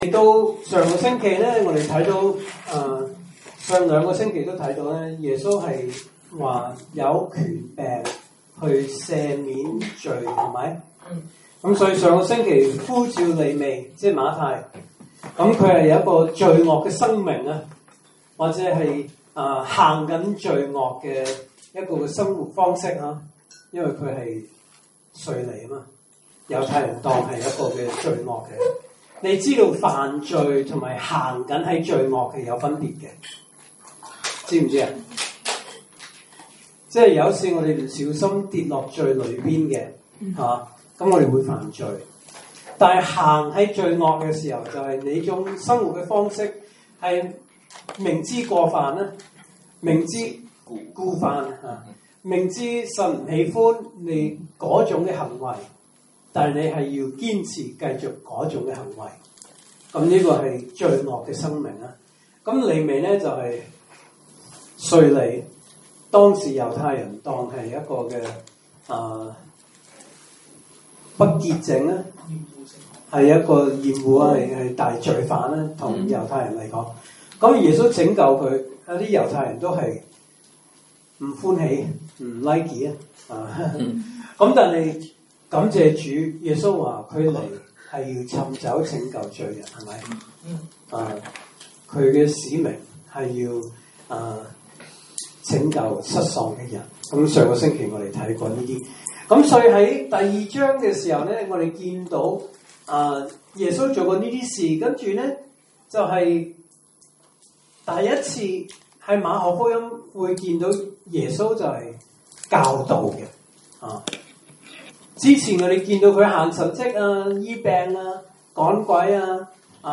證道信息
來自講道系列 "查經班：馬可福音"